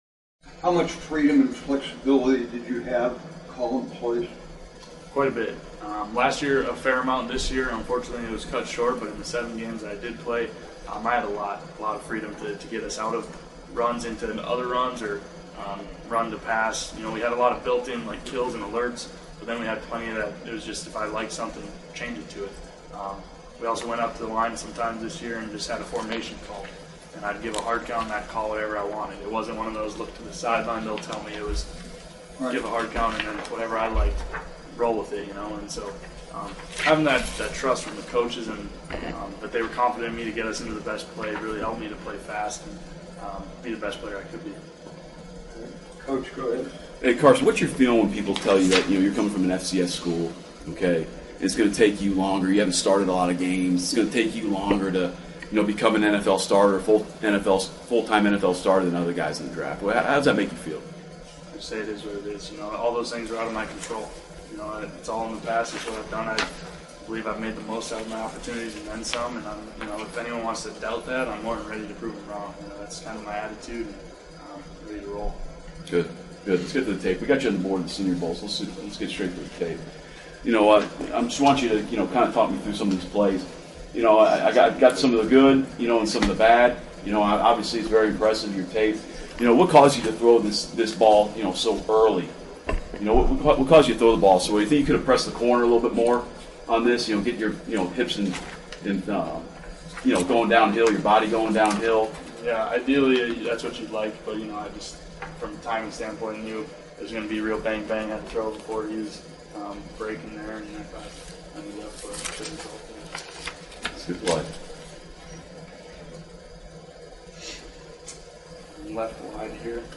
DRAFT17-Wentz-Combine-Interview-32k.mp3